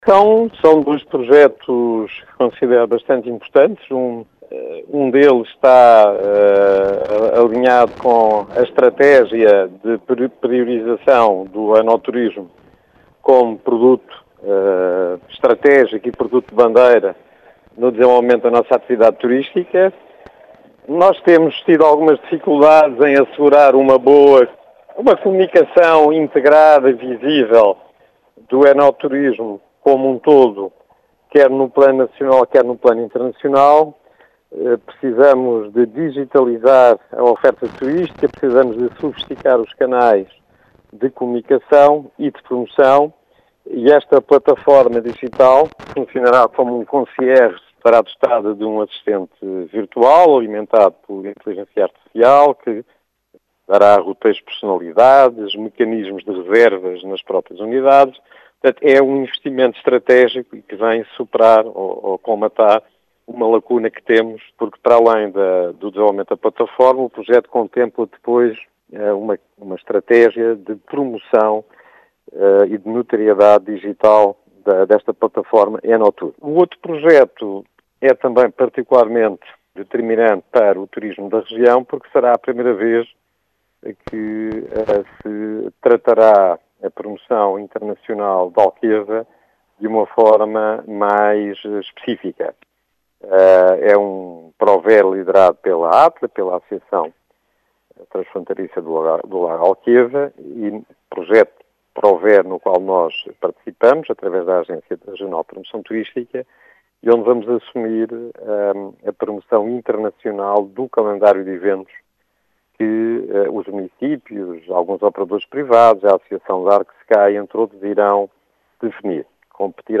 “Dois projetos bastante importantes “segundo explicou à Rádio Vidigueira, José Santos, presidente da Entidade Regional de Turismo do Alentejo.